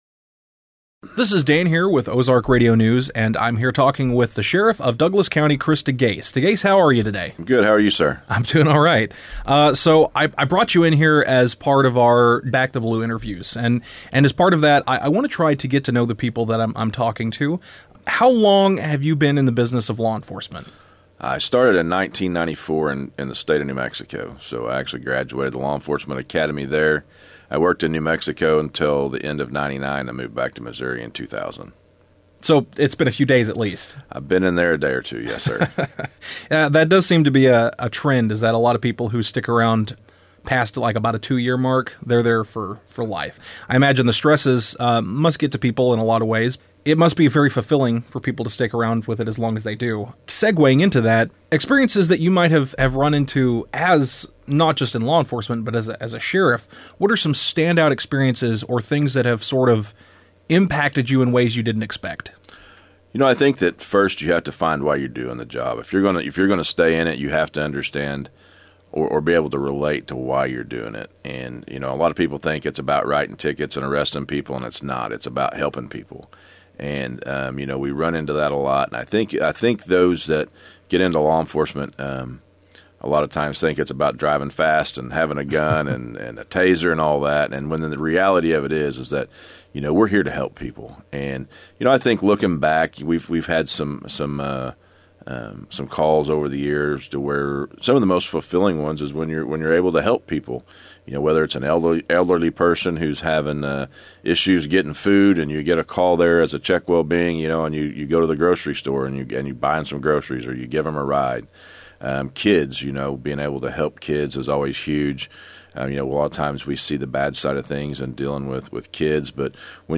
Degase-interviewMP3.mp3